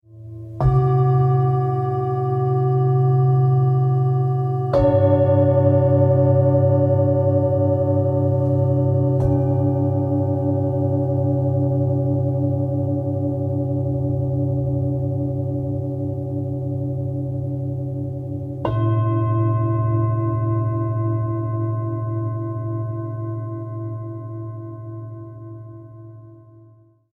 gong.ogg